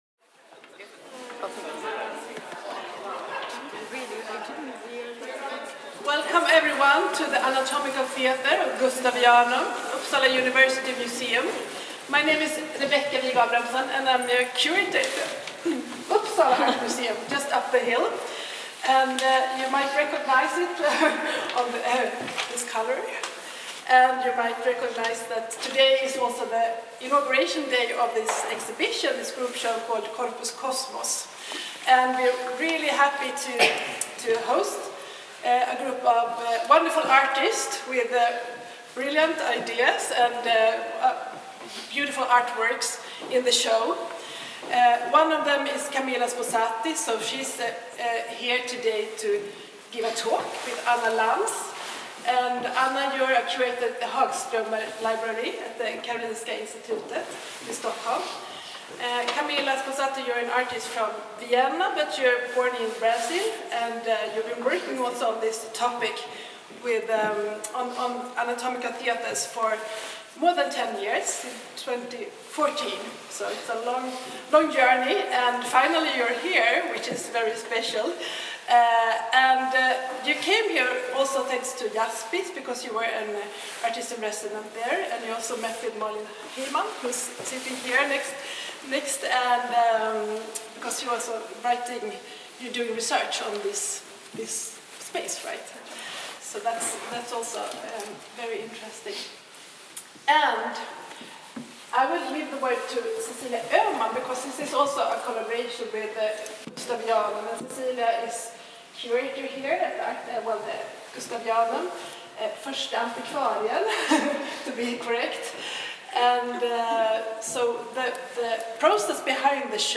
Samtal: Att levandegöra den anatomiska teatern i Uppsala
Samtalet arrangerades på vernissagedagen av utställningen Corpus Cosmos 15 mars 2025.